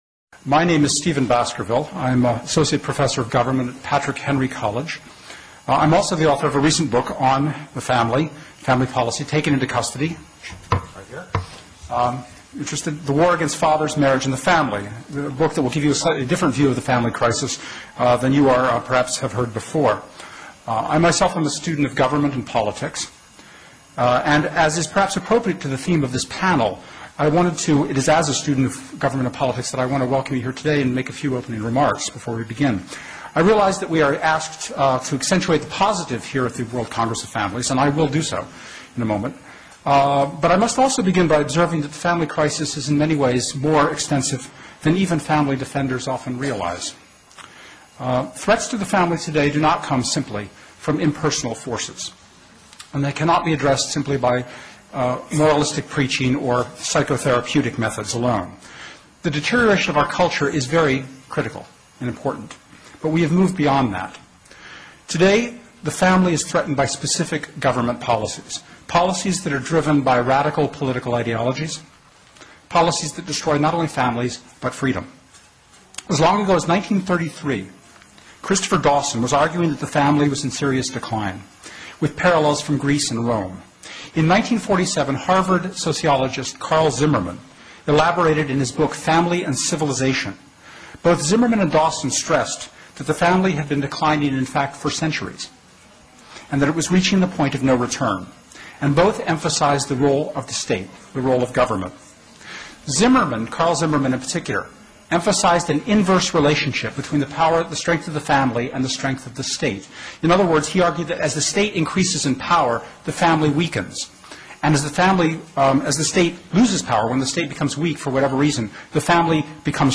World Congress of Families Speech BOOK REVIEW - Carle C. Zimmerman Family and Civilization Divorced from Reality Newsweek's lies about divorce Men: The New Victim Group Same-sex marriage and polygamist cults Five Myths about No Fault Divorce How Our Tax Dollars Subsidize Family Breakup Taken into Custody Promo How to Turn a Free People into Slaves The Failure of 'Family Policy' Totalitarianism in America From Welfare State to Police State Do Not Marry Do Not Have Children Some are more equal than others Welfare Funding Obstructs Shared Parenting Welfare and the 'Road to Serfdom' Alec Baldwin and Parens Patriae Duke Case Demonstrates Feminist 'Justice' Hillary and the Politics of Children Vilsack has chance to emerge as family-issues candidate Does the National Fatherhood Initiative Get It Right on Fatherhood?